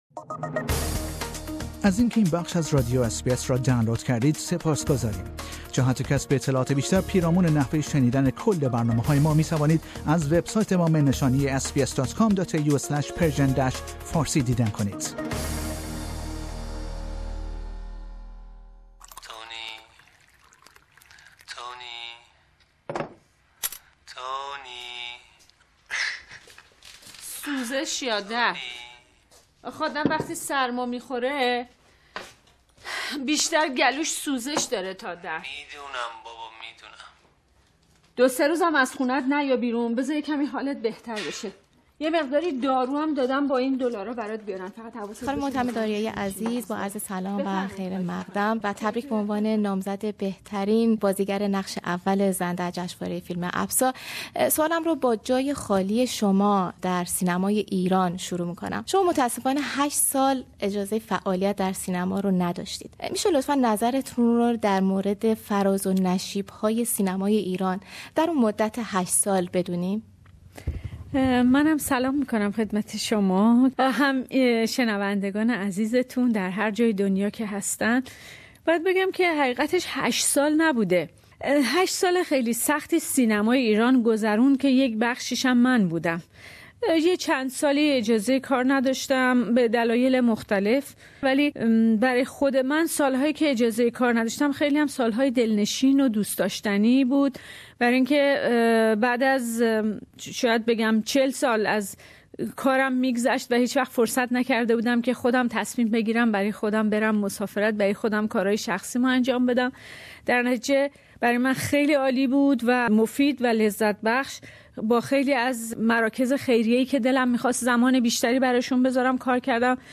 او در گفتگوی اختصاصی با بخش فارسی رادیو اس بی اس درباره این جایزه و فعالیت های هنری خود سخن گفته است.